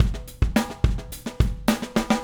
Retro Funkish Beat 01 Fill B.wav